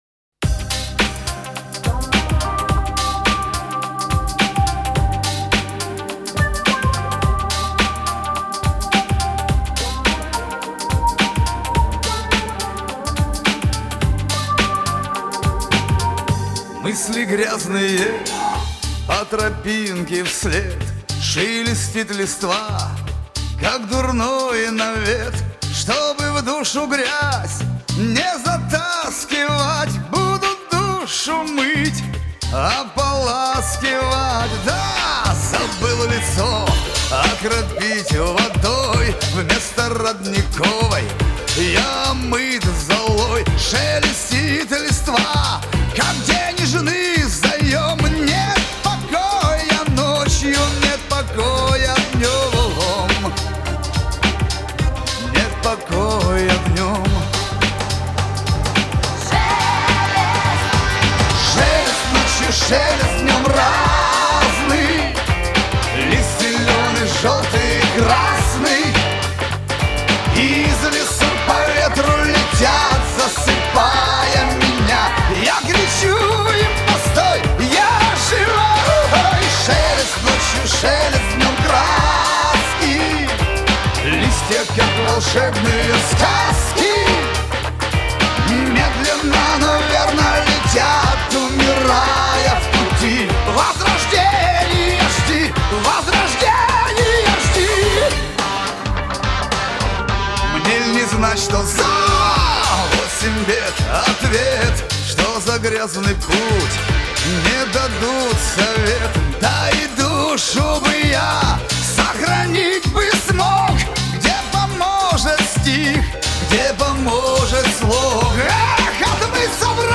ремикс - он и в Африке Remix